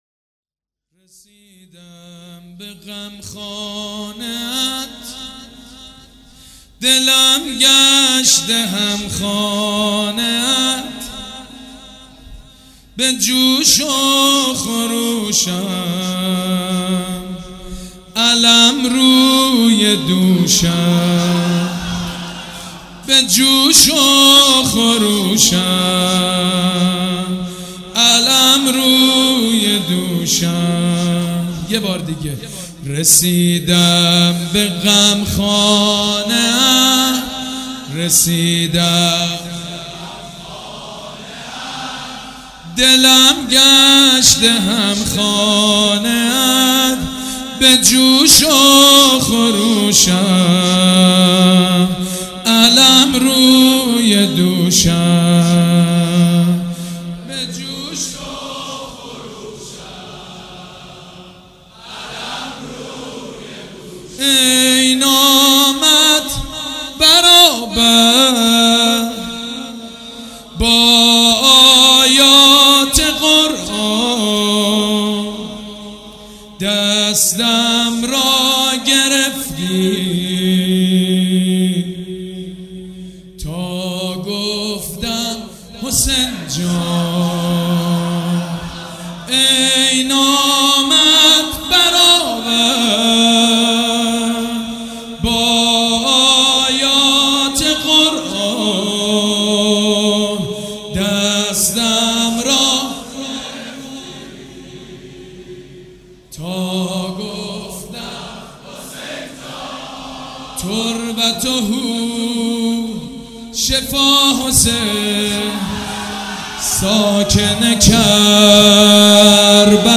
شب چهارم محرم الحرام‌
نوحه
مداح
حاج سید مجید بنی فاطمه
مراسم عزاداری شب چهارم